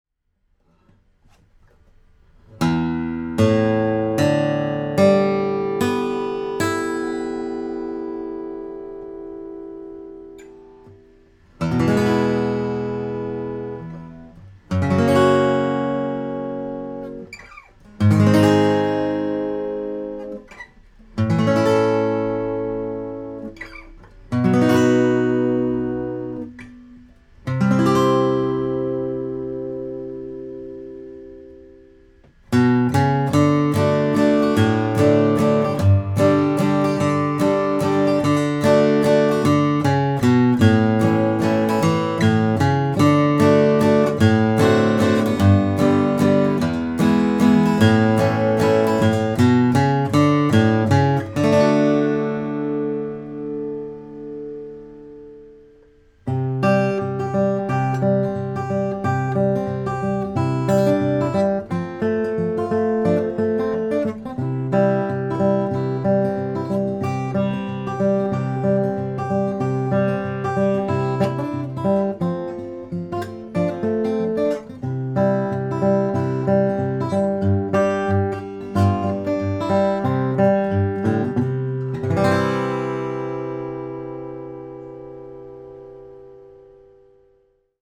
Red Maple Grand Concert
This grand concert puts out a tremendous amount of sound. It has a nice, open bottom end but is still very balanced, without sacrificing the sweet maple-y high end.